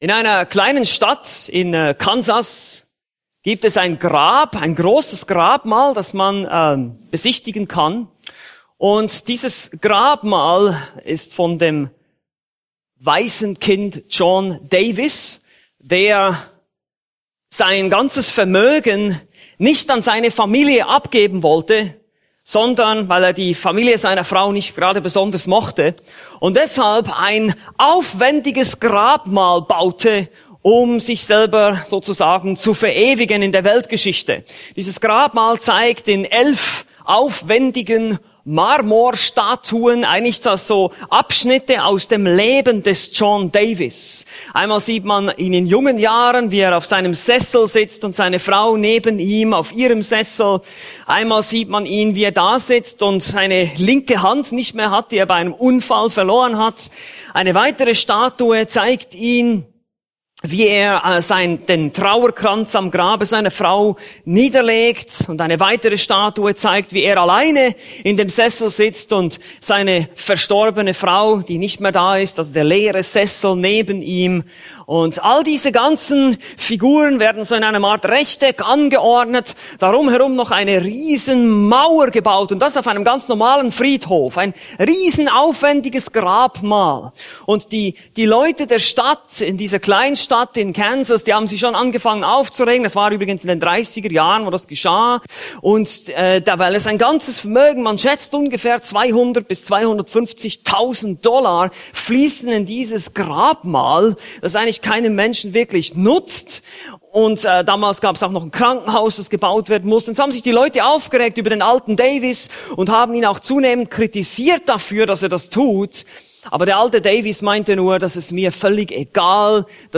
Predigten Übersicht nach Serien - Bibelgemeinde Barnim